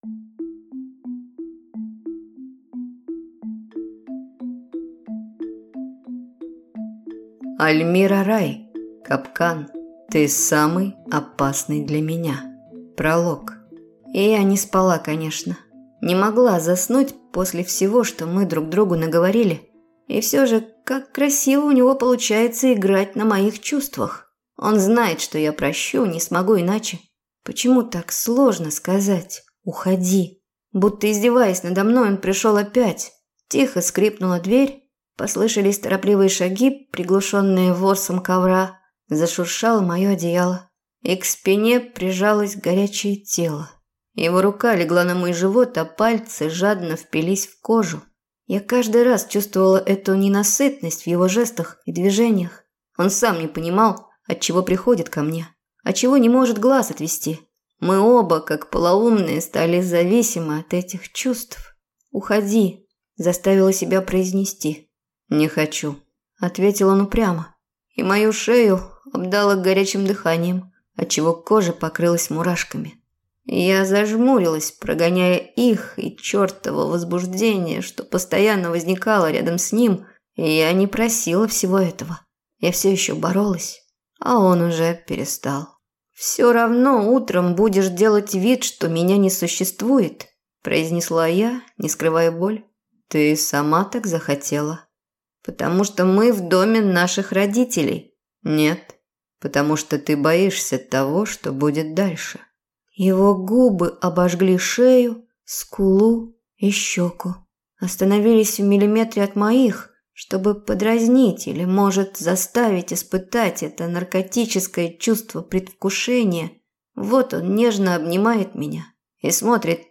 Аудиокнига Капкан. Ты самый опасный для меня | Библиотека аудиокниг